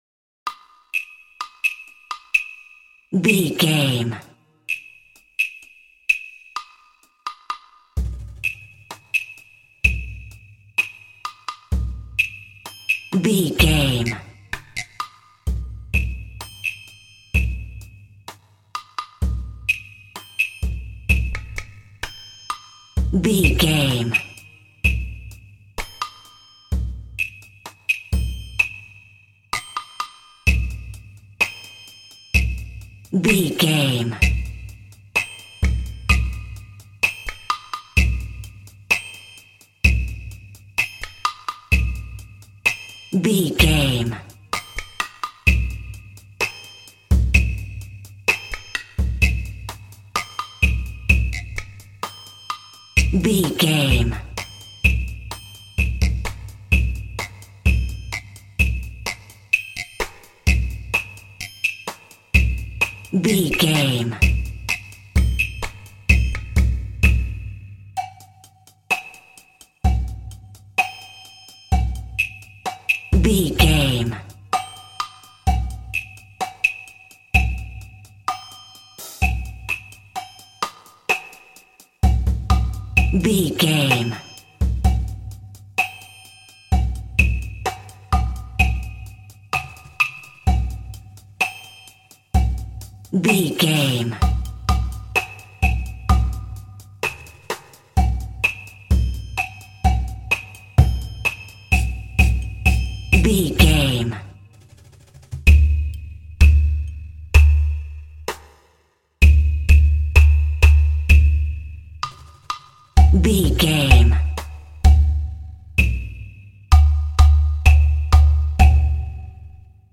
This quirky track features a bassoon and plucked strings.
Aeolian/Minor
funny
playful
foreboding
suspense
contemporary underscore